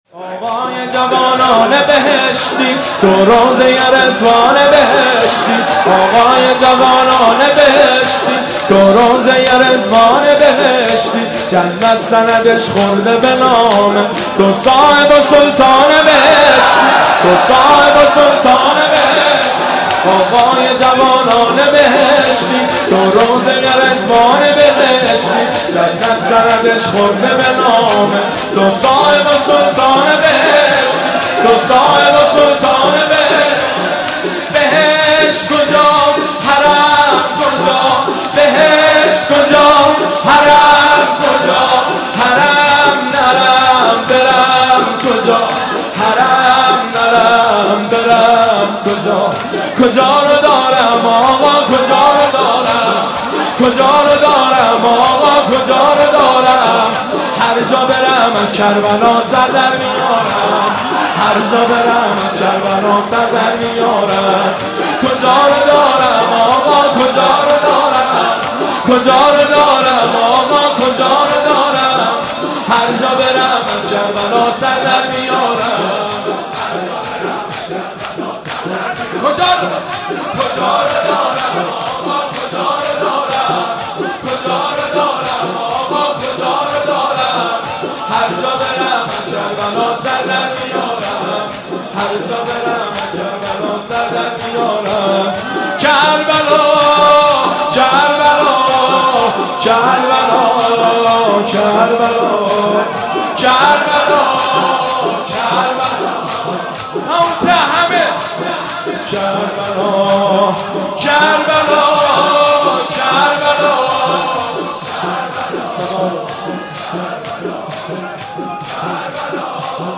شب اول محرم 1392
هیئت خادم الرضا (ع) قم